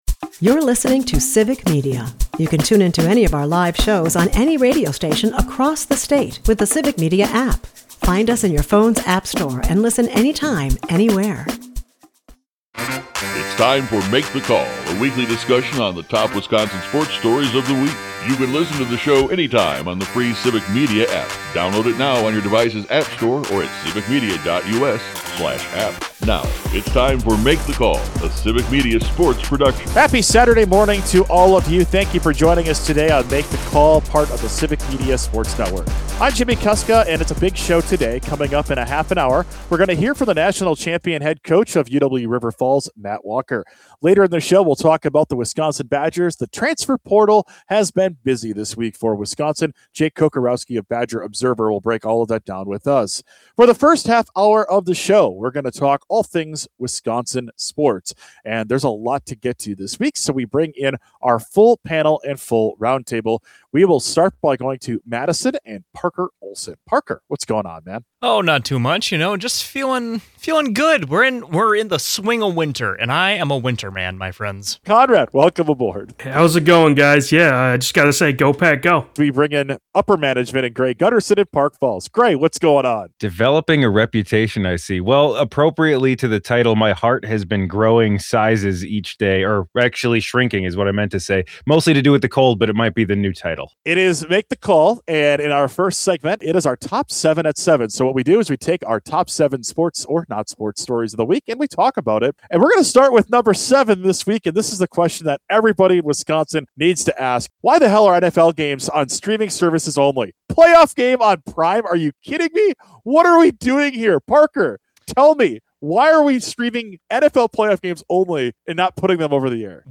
The NFL Playoffs are here and the roundtable is ready for Packers vs Bears round three, which the guys can’t believe will only be on Amazon Prime. Also, a little news in the world of Giannis as he reiterates he is committed to the Bucks.